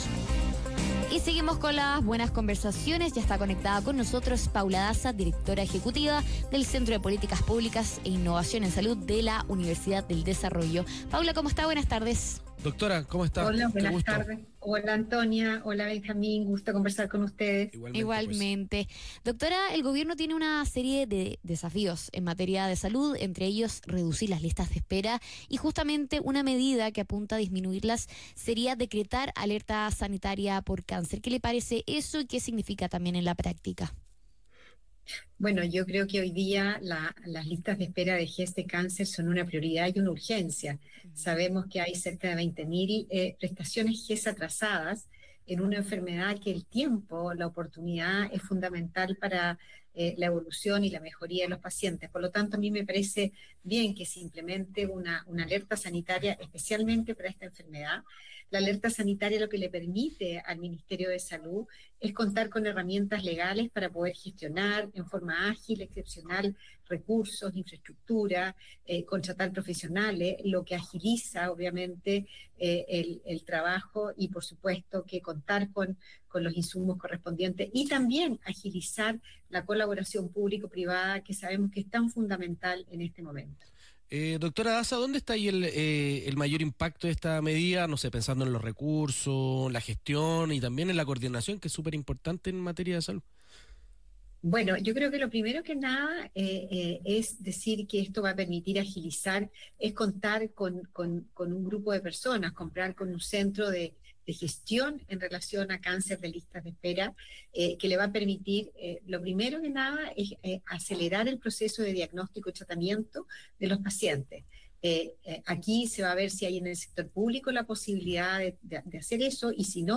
Radio Infinita | Paula Daza conversa sobre alerta sanitaria por cáncer para reducir listas de espera